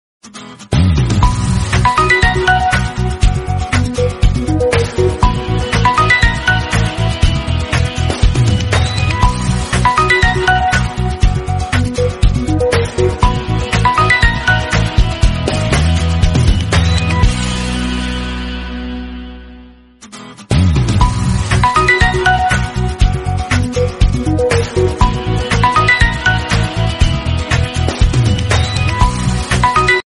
Samsung M12 Notification Sound Effect Free Download
Samsung M12 Notification